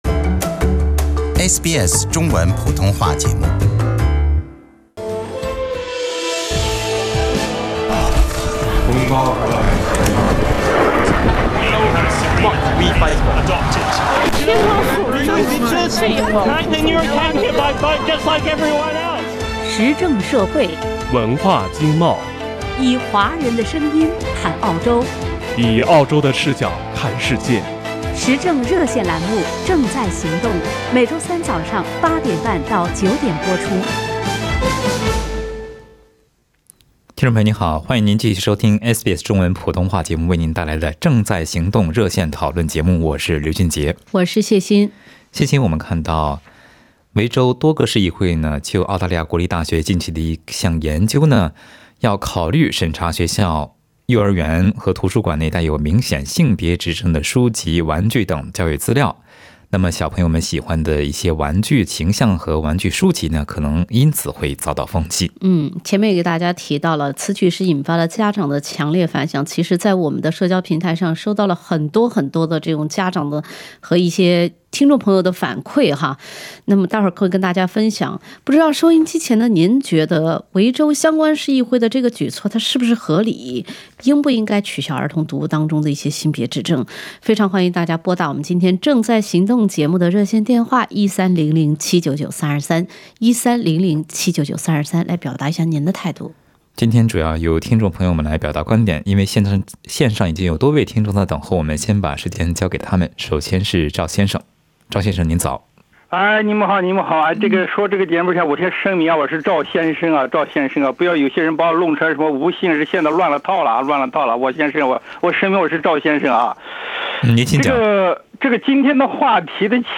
听众朋友在本期的《正在行动》节目中表达了自己的看法。